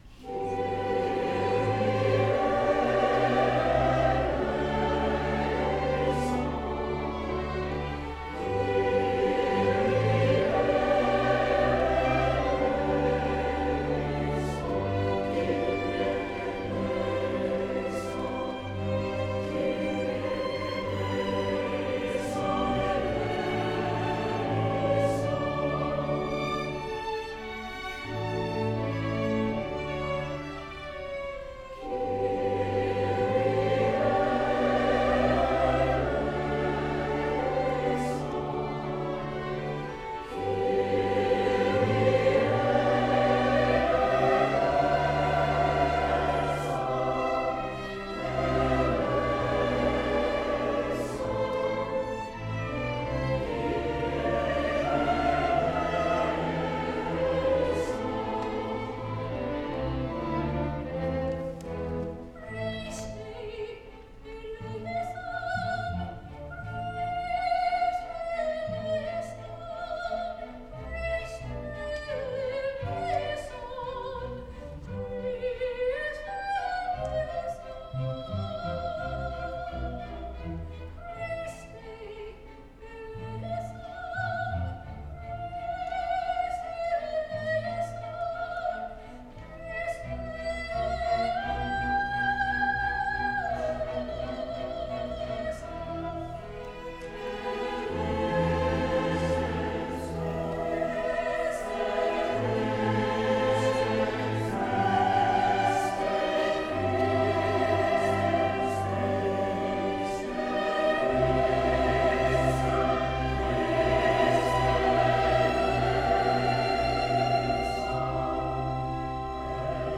soprano
Today’s presentation features the original version of the second mass, calling for string orchestra and organ along with soprano, tenor, and baritone soloists and choir.